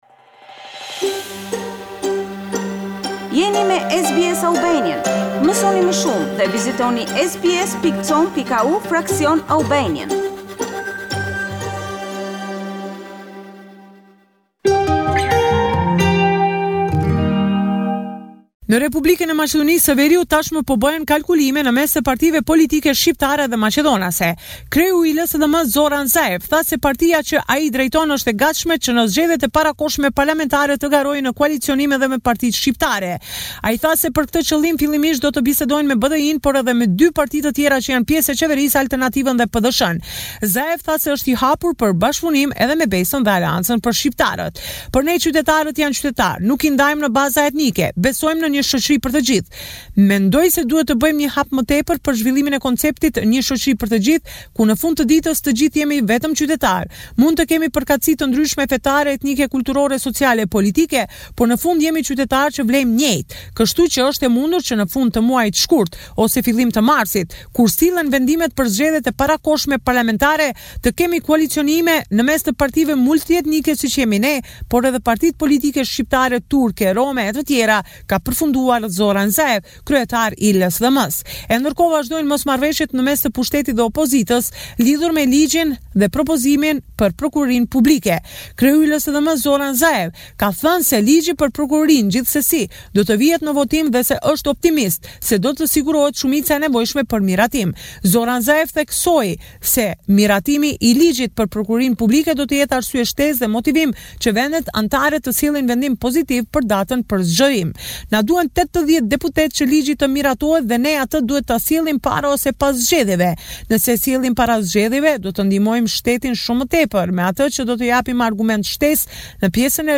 This is a report summarising the latest developments in news and current affairs in North Macedonia